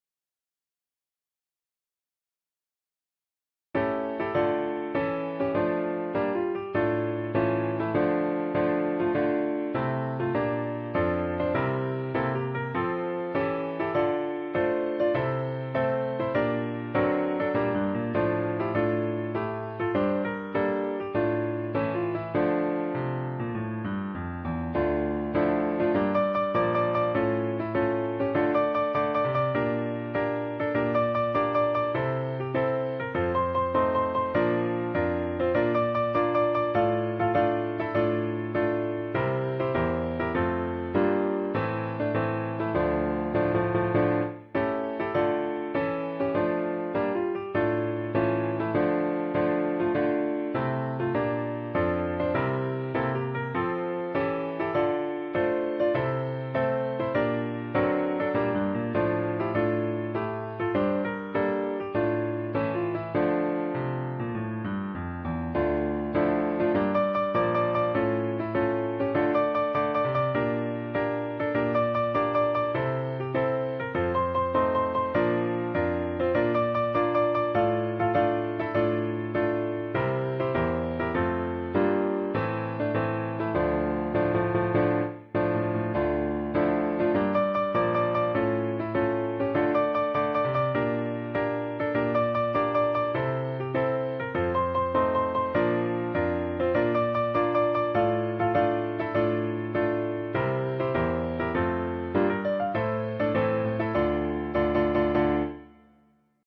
на фортепиано